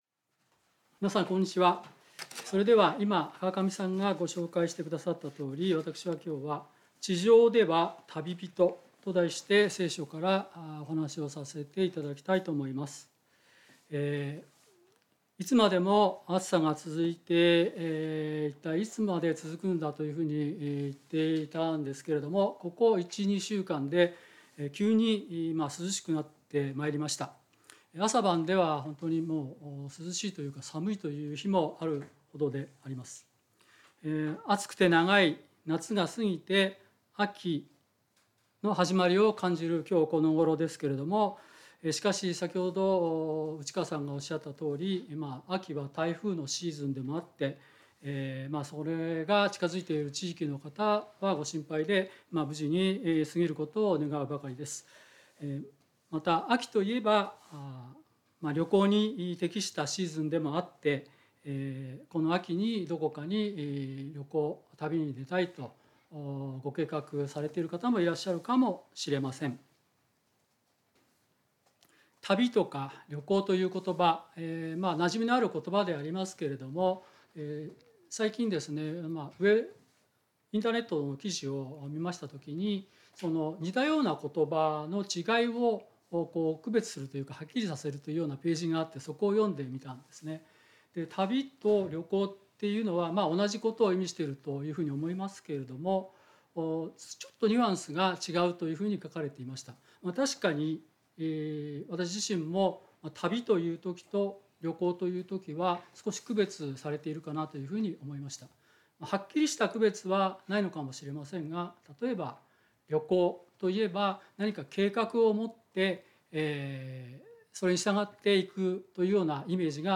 聖書メッセージ No.289